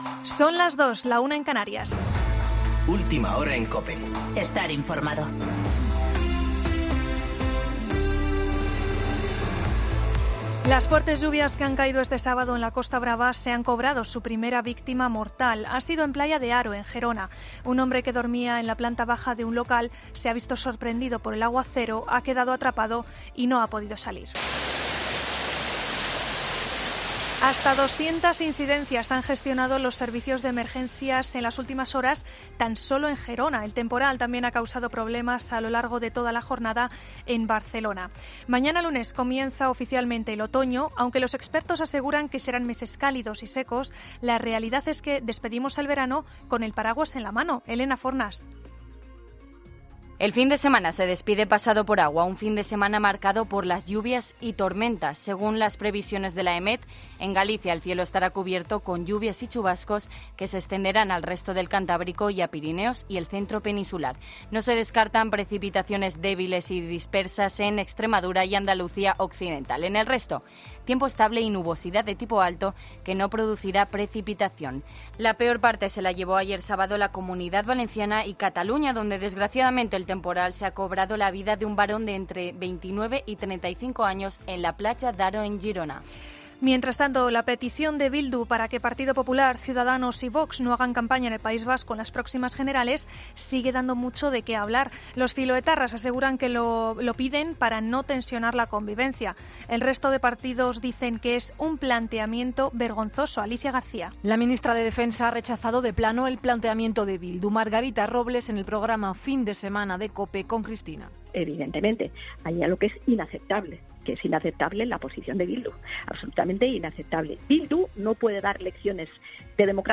Boletín de noticias COPE del 22 de septiembre a las 02.00